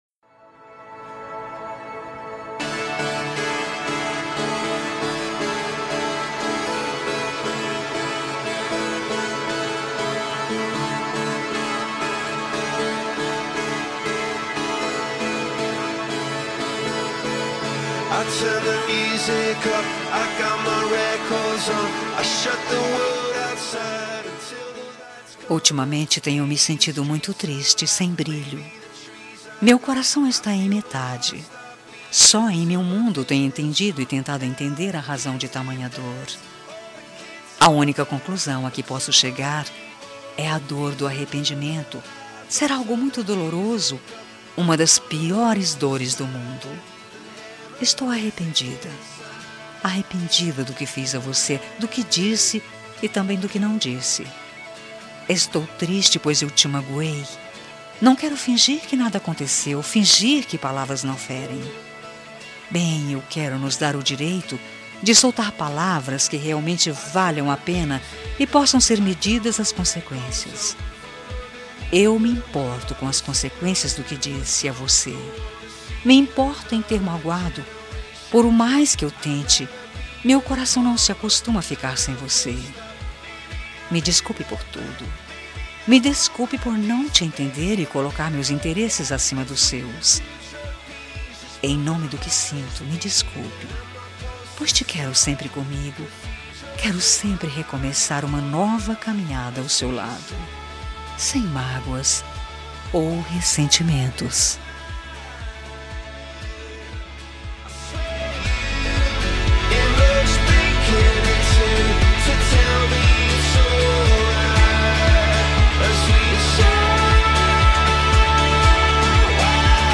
Telemensagem de Desculpas – Voz Feminina – Cód: 202037